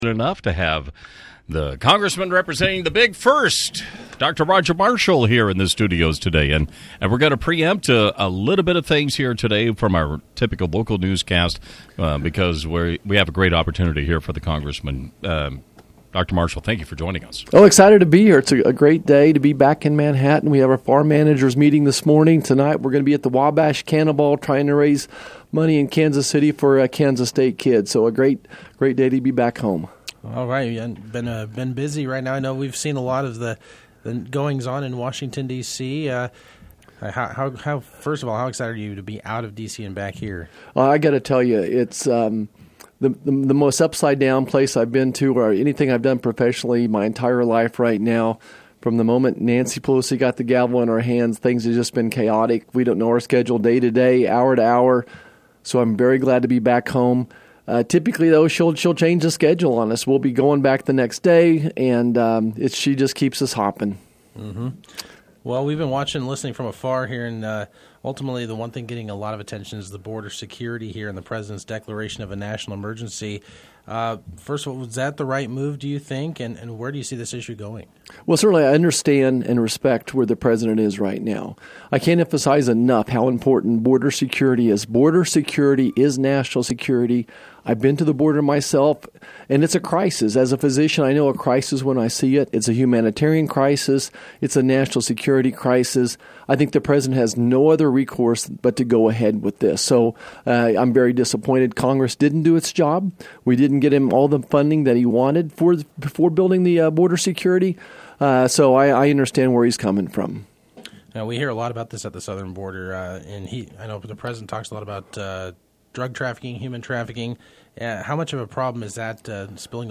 U.S. Rep. Roger Marshall for Kansas’ 1st district called in to KMAN during Friday’s morning show. He discussed border security, a proposal that would allow the Kansas Farm Bureau to offer health insurance, as well as the upcoming campaign for retiring Sen. Pat Robert’s seat.